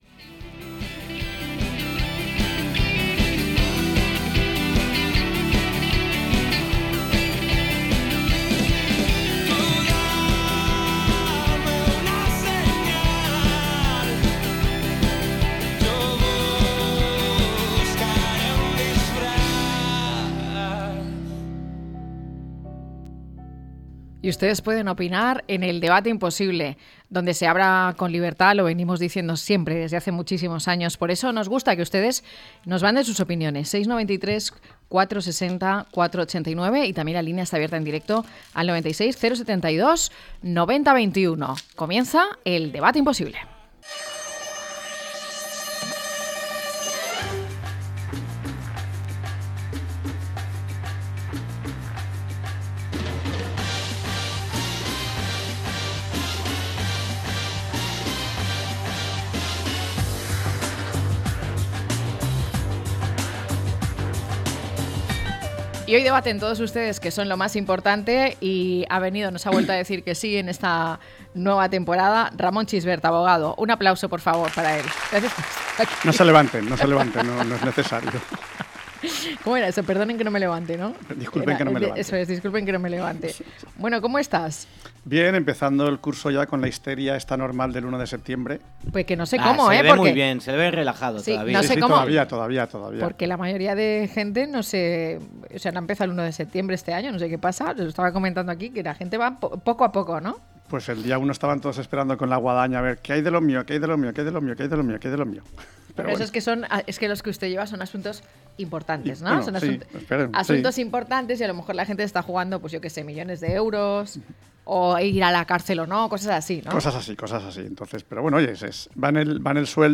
0903-EL-DEBATE-IMPOSIBLE.mp3